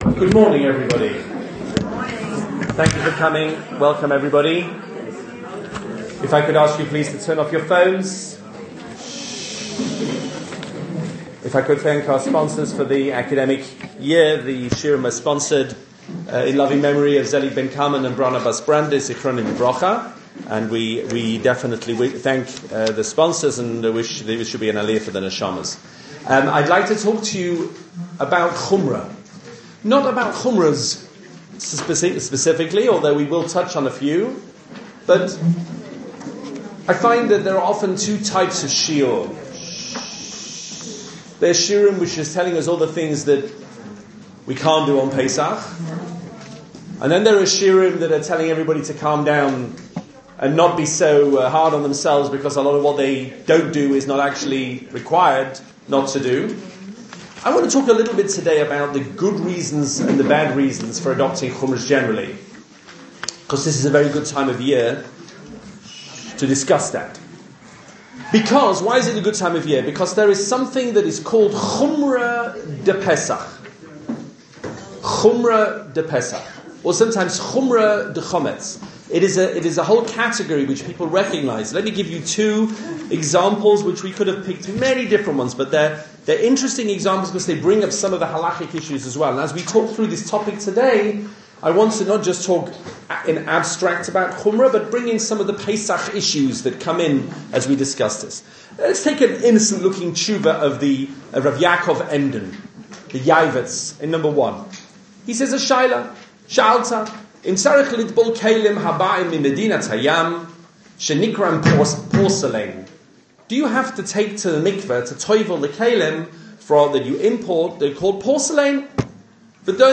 Two shiurim